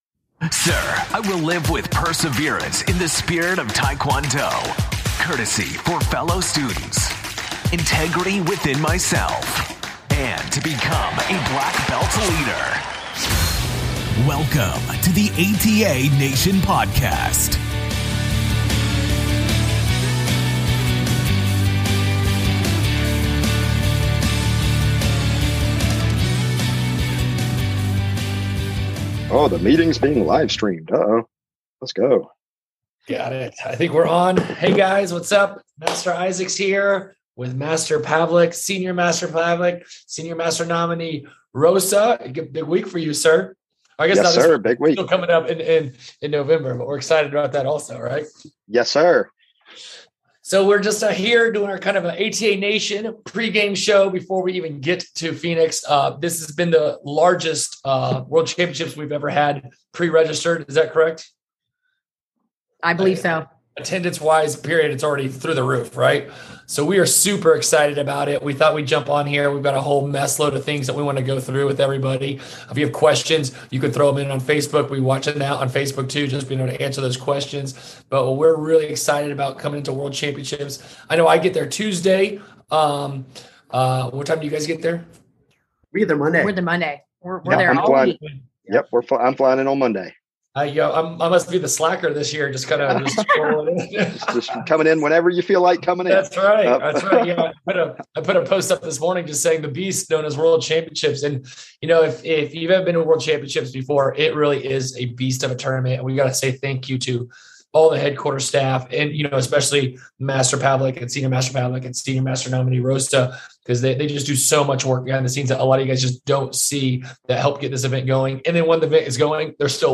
We've partnered with the ATA Nation Network to rebroadcast their video show in audio formate during the ATA World Championships.